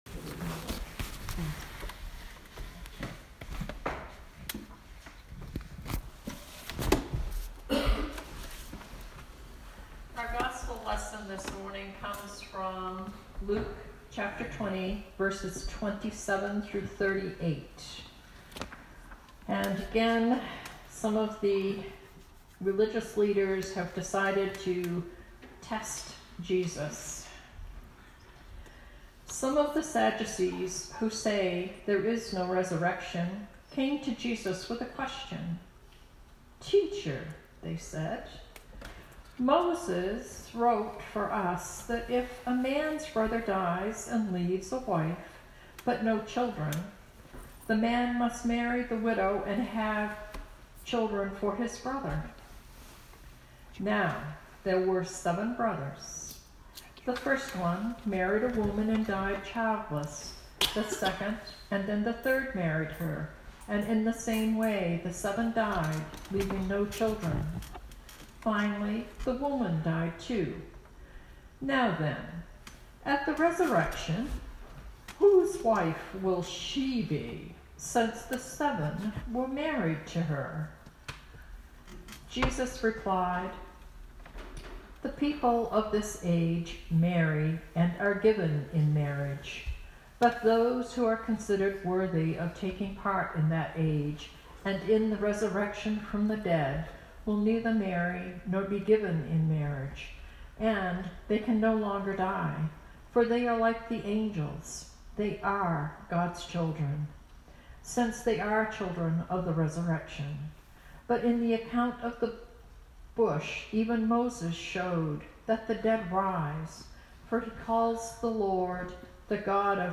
Sermon 2019-11-10